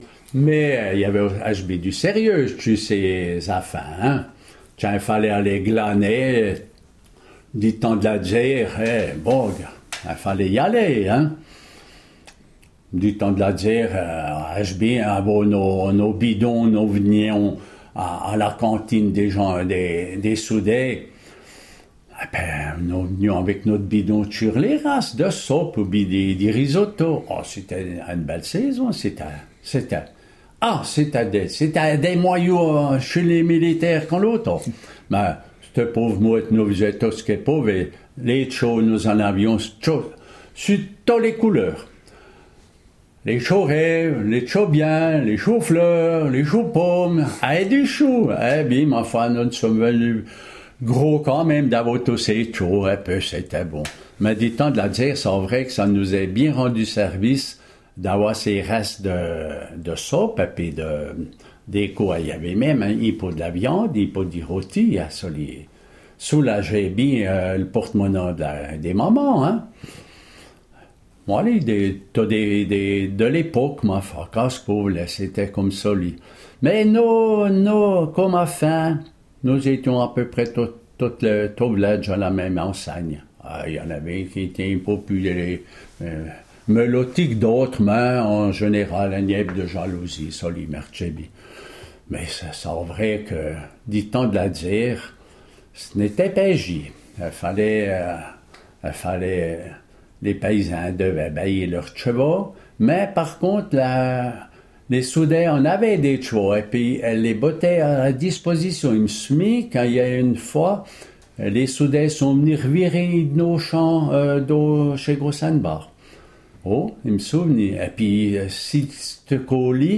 En patois de Courchapoix, Val Terbi